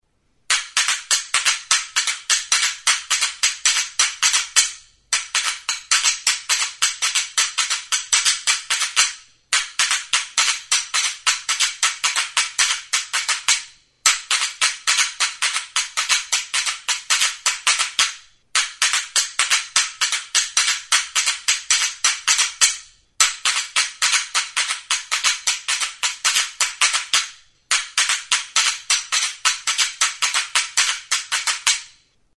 CUNCHAS; RASCADOR; VIEIRA | Soinuenea Herri Musikaren Txokoa
RITMO DE MUÑEIRA.
Enregistré avec cet instrument de musique.
Instruments de musique: CUNCHAS; RASCADOR; VIEIRA Classification: Idiophones -> Frottés Emplacement: Erakusketa; idiofonoak Explication de l'acquisition: Erosia; Galizia-ra egindako bidai batean erosiak.